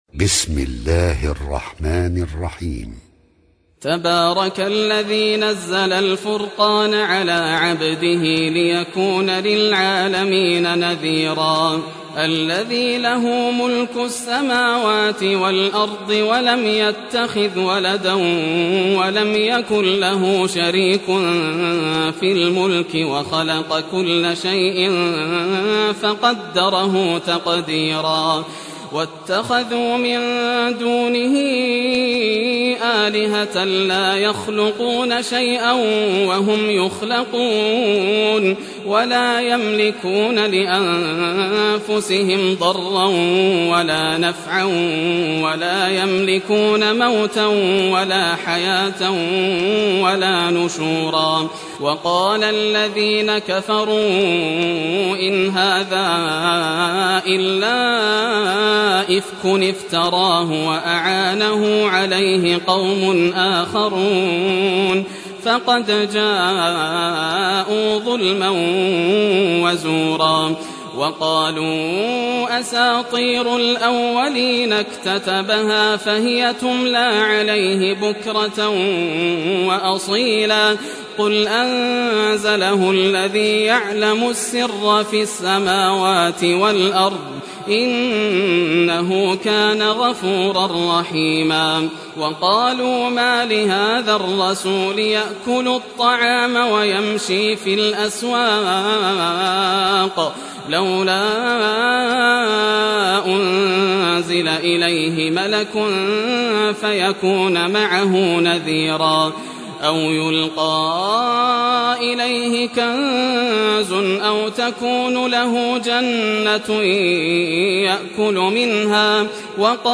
Surah Al-Furqan Recitation by Yasser al Dosari
Surah Al-Furqan, listen or play online mp3 tilawat / recitation in Arabic in the beautiful voice of Sheikh Yasser al Dosari.